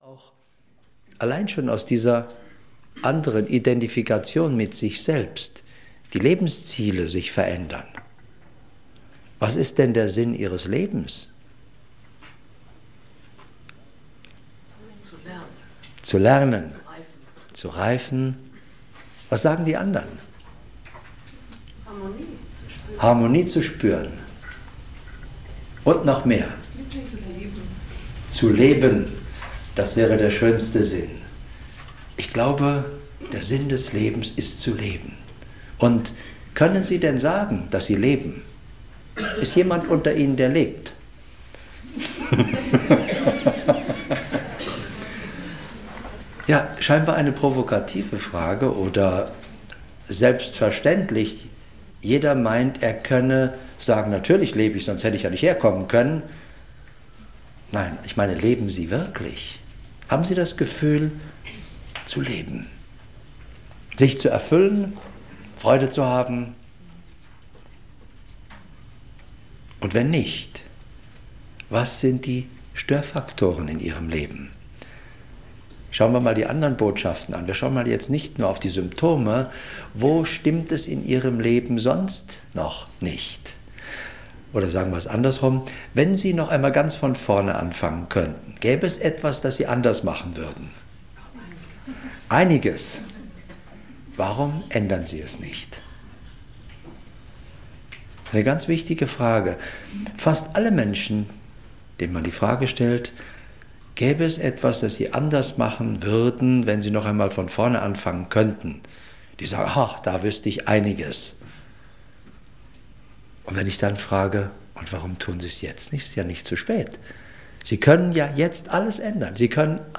Long-Seminar-Classics - Was uns Krankheit sagen will - Hörbuch